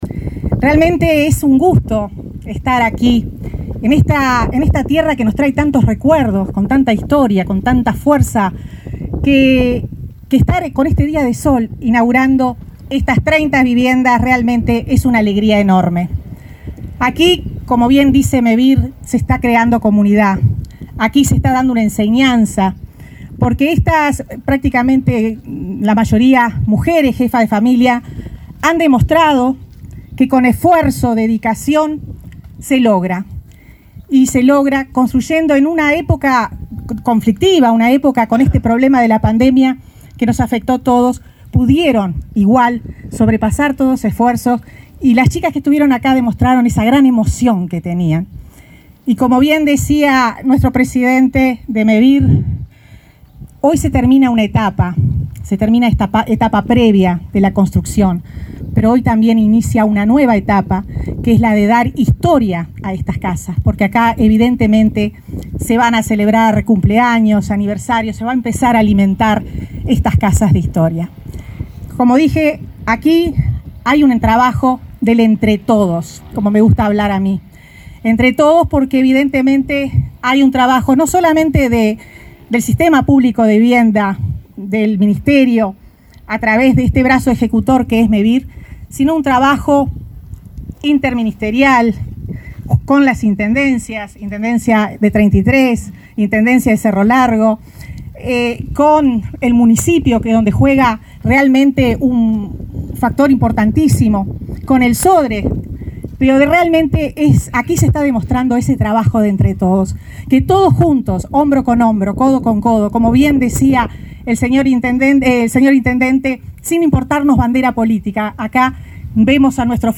Oratoria del secretario de Presidencia, Álvaro Delgado, y de la ministra de Vivienda, Irene Moreira
El Movimiento de Erradicación de la Vivienda Insalubre Rural (Mevir) inauguró, este viernes 13, un complejo de 30 viviendas en Santa Clara de Olimar,